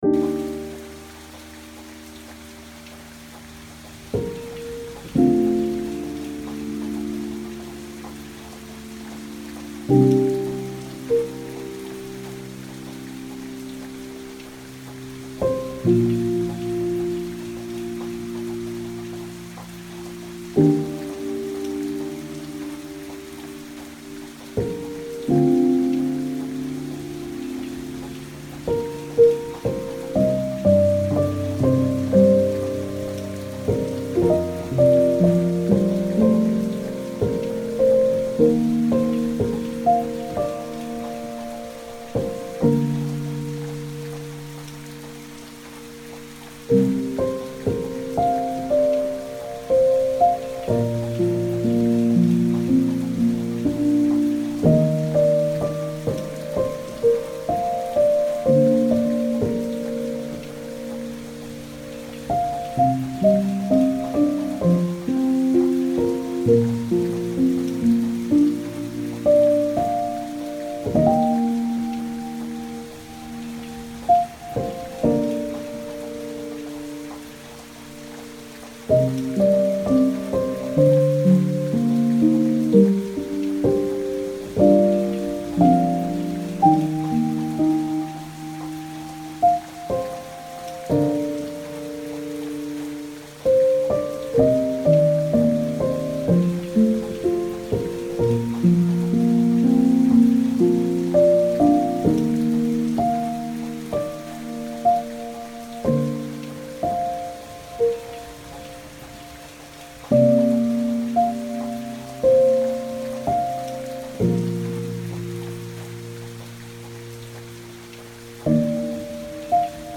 Indoor Hard Rain Sound